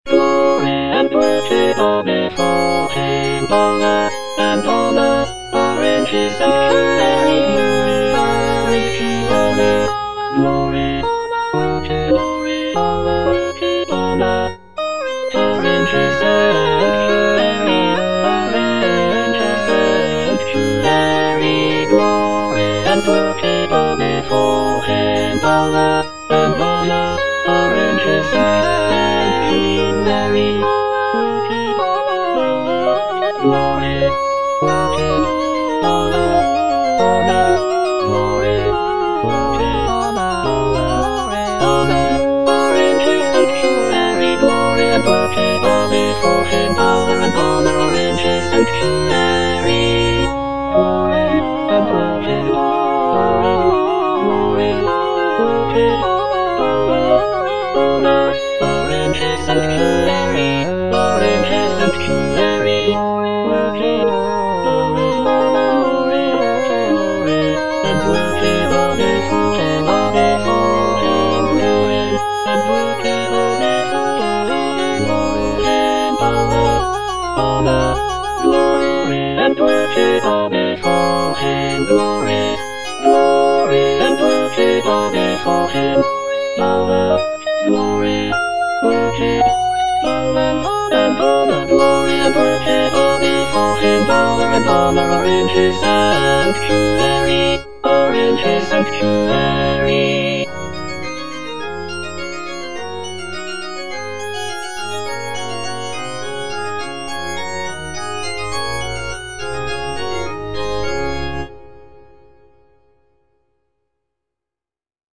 (A = 415 Hz)
(All voices)